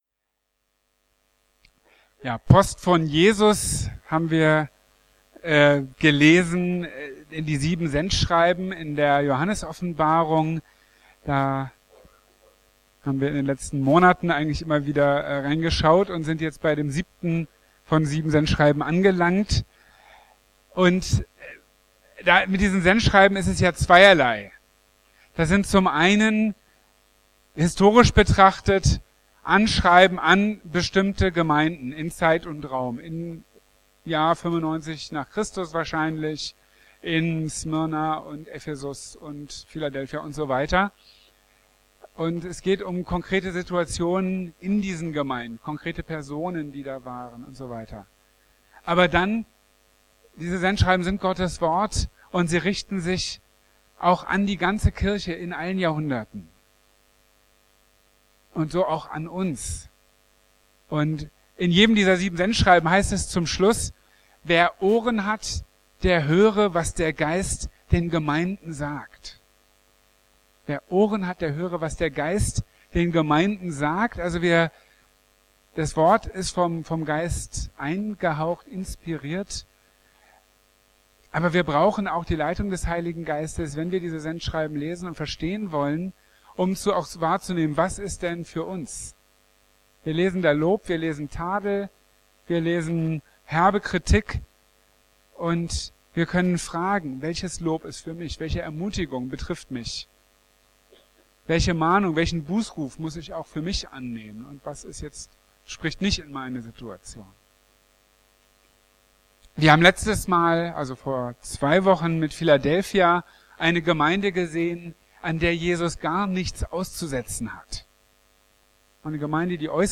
Weder kalt noch heiß (Sendschreiben, Teil 7) | Marburger Predigten